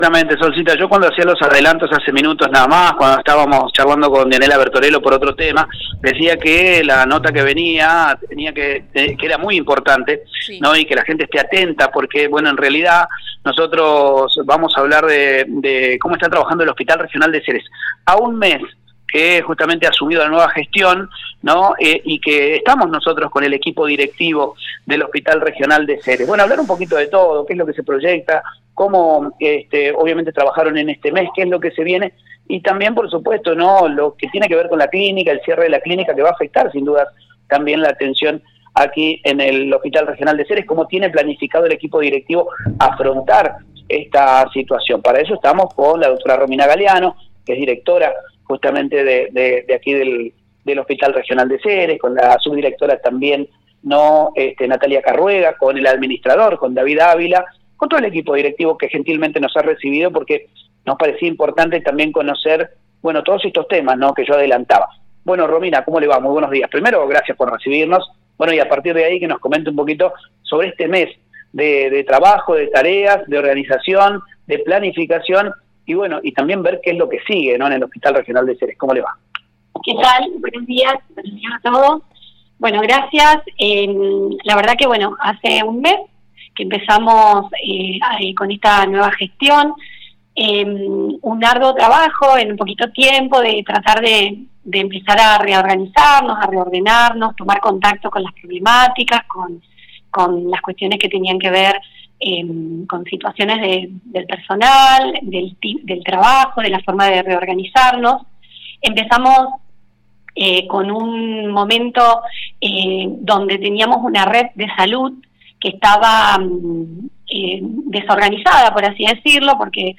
A un mes de gestión, el equipo Directivo del Hospital Regional de Ceres nos brindó una entrevista exclusiva, para contar sobre el trabajo de reorganización en el sistema de turnos, reorganización de guardias y el desafío que enfrenta el sistema público de salud con el cierre del sector privado.